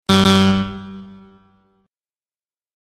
クリック音や決定音など、エラーとしても効果的に使用できる効果音。